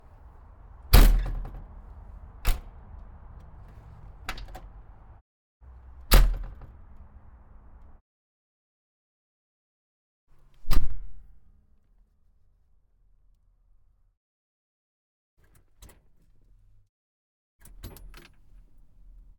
Pole Position - Volvo L90c Wheel Loader 1996